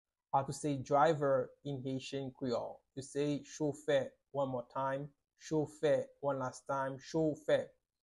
How to say "Driver" in Haitian Creole - "Chofè" pronunciation by a native Haitian teacher
“Chofè” Pronunciation in Haitian Creole by a native Haitian can be heard in the audio here or in the video below:
How-to-say-Driver-in-Haitian-Creole-Chofe-pronunciation-by-a-native-Haitian-teacher.mp3